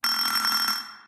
BuzzerBell.ogg